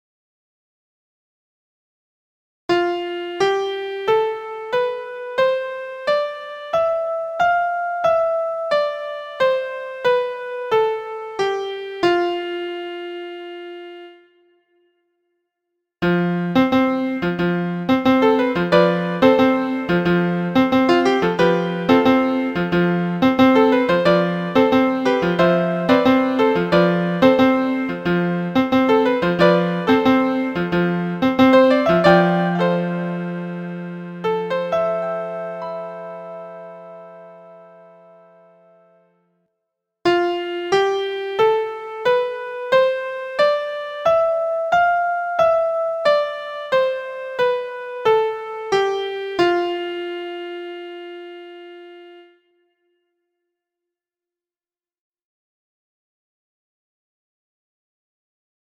LYDIAN
Lydian.mp3